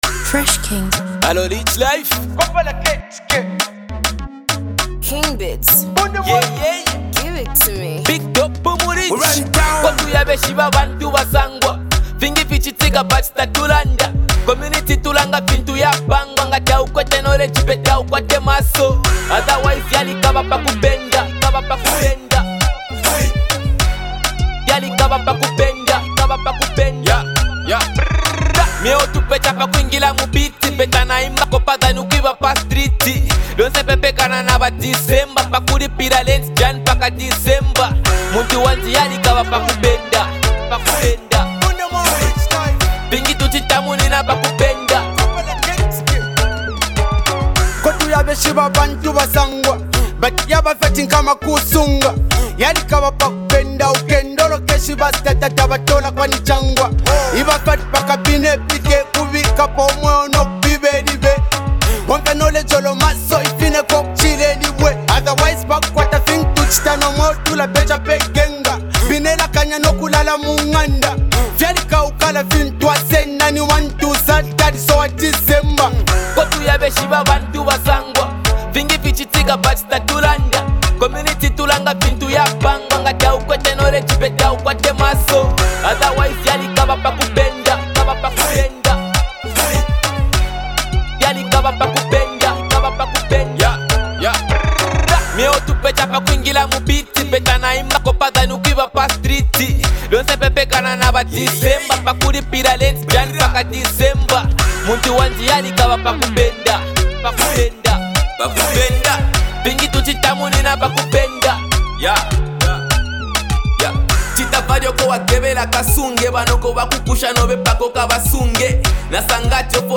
a copperbelt ravity raper